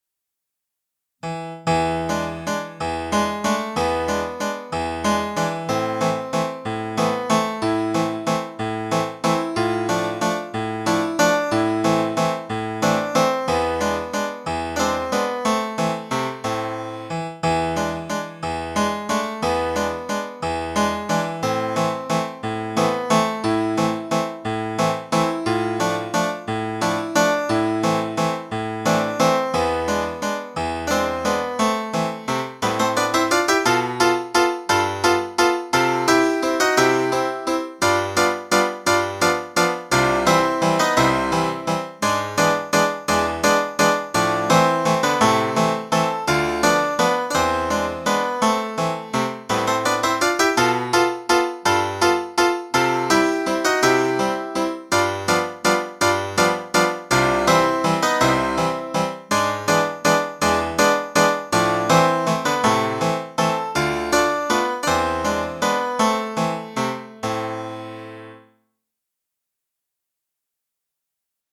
彼の作品は主に踊り（ワルツ、カドリル、ポーク）と行進曲。